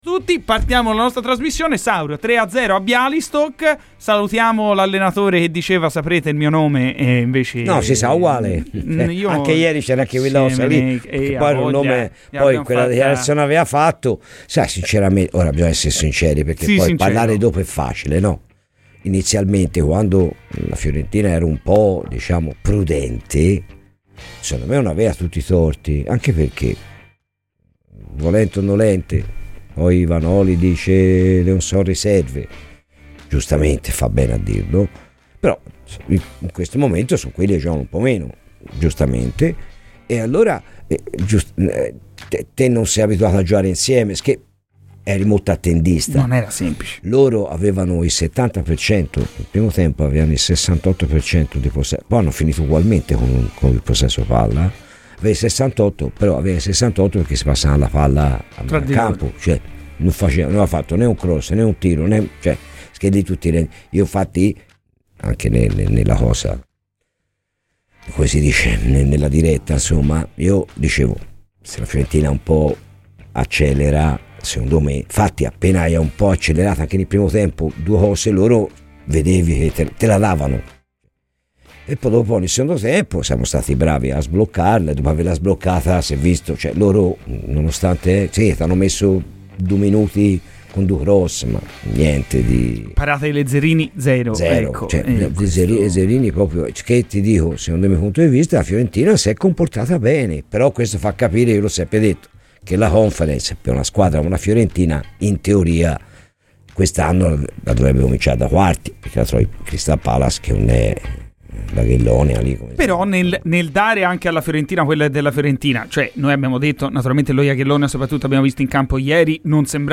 è stato ospite negli studi di Radio FirenzeViola